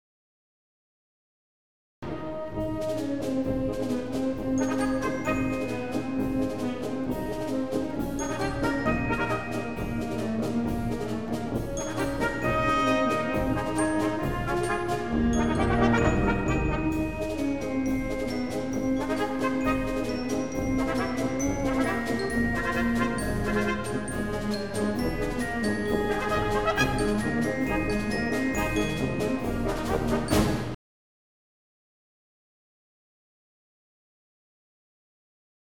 Obsérvese en el siguiente ejemplo, una marcha de John Philip Sousa, la presencia de tres temas, que como es habitual los denominamos con letras diferentes: A, B y C. Puede notarse cómo cada uno de ellos es repetido de manera inmediata hasta pasar a otro diferente (el último tema se interpreta hasta tres veces). Sin embargo, los temas B y C no se repiten siempre igual, sino que varía la instrumentación en cada una de sus presentaciones, para dar así mayor variedad a la música y que no resulte excesivamente repetitiva.